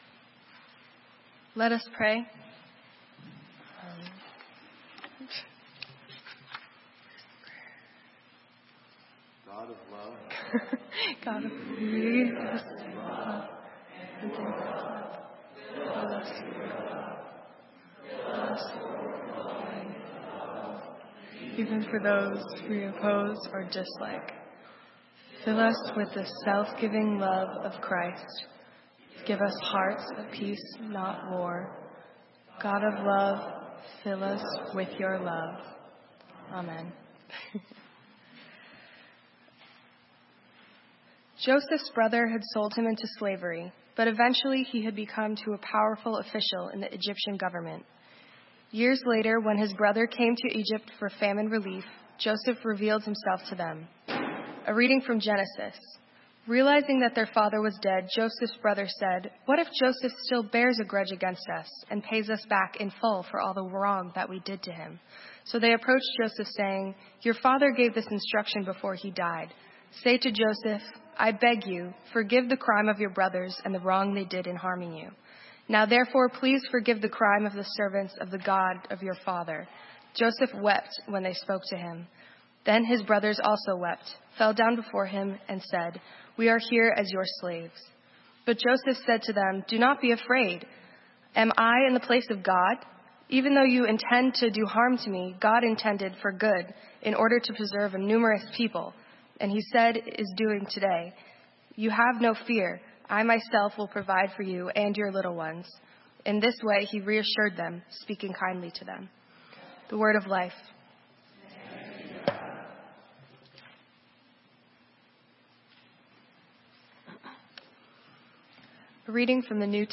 Sermon:Love only - St. Matthew's UMC